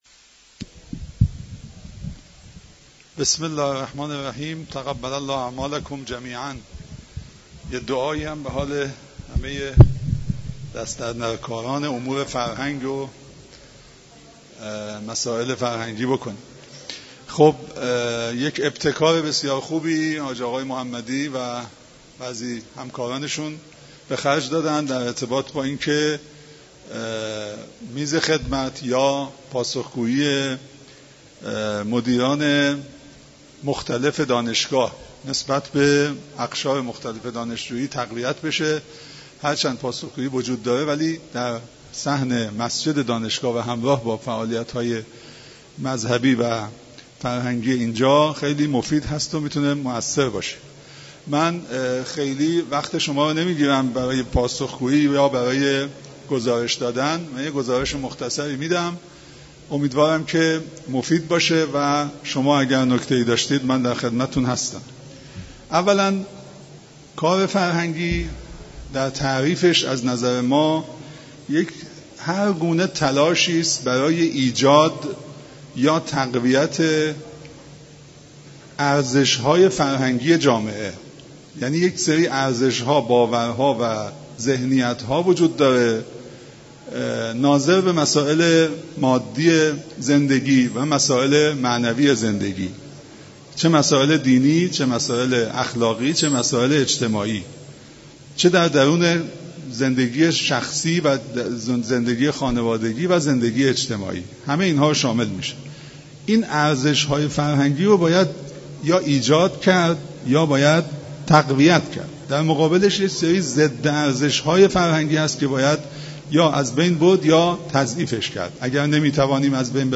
جلسه تبیین برنامه های فرهنگی دانشگاه در مسجد دانشگاه کاشان برگزار گردید